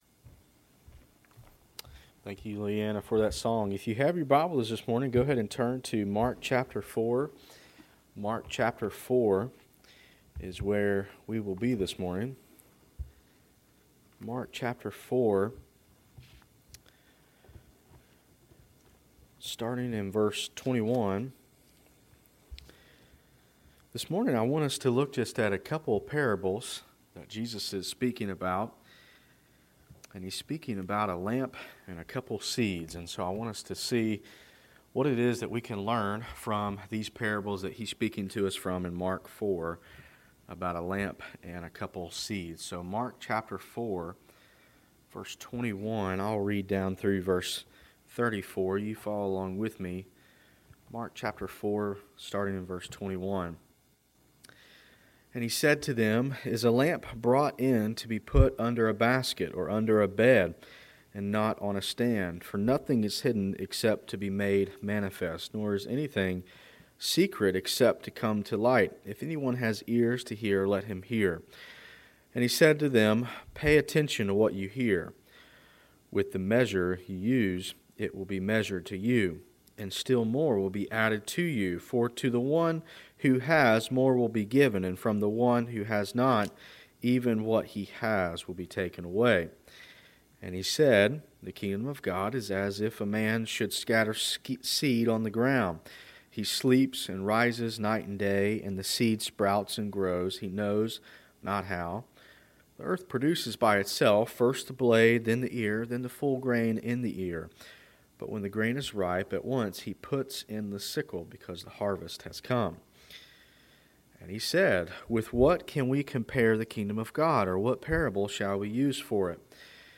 Sermons - Tippett's Chapel Free Will Baptist Church